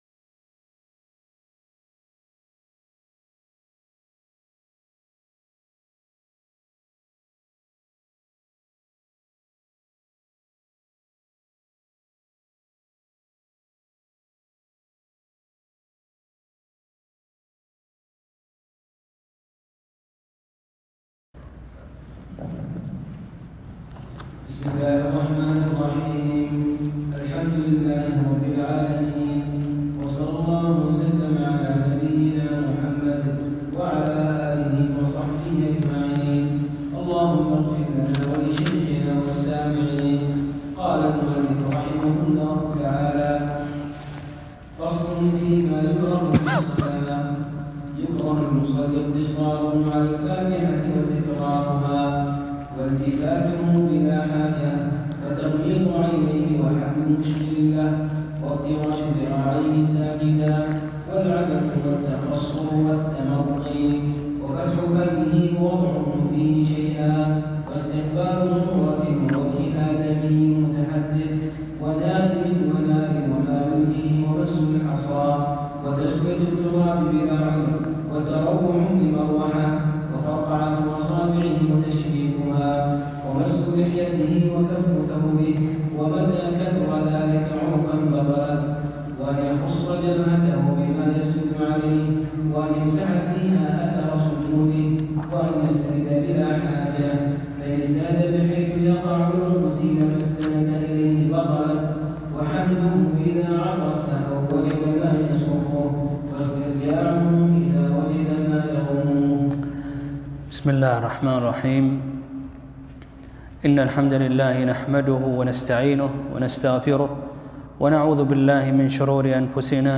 الدرس ( 17) مكروهات الصلاة - كتاب دليل الطالب